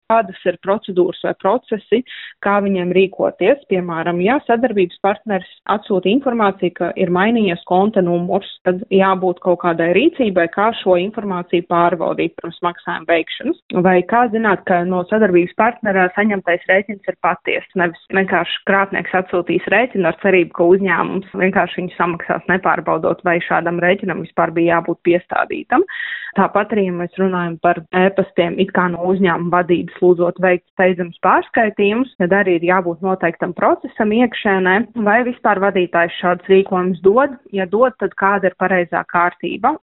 intervijā